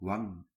Bilabial approximant
Dutch Southern[24] wang
[β̞aŋ] 'cheek' Labiovelar [ʋ] in northern Dutch.